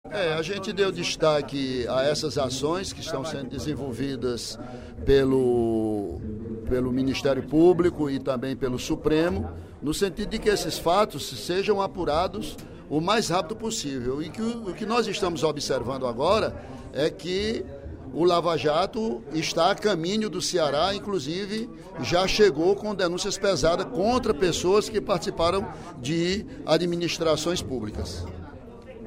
O deputado Ely Aguiar (PSDC) comentou, durante o primeiro expediente da sessão plenária desta terça-feira (18/04), possíveis desdobramentos da Operação Lava Jato no Ceará.